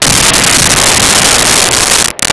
Kaboom22
KABOOM22.WAV